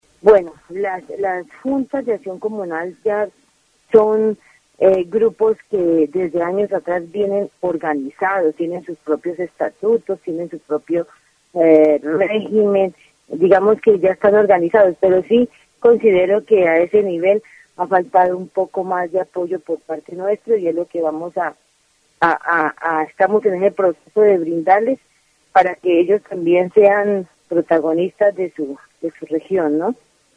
dc.subject.lembProgramas de radio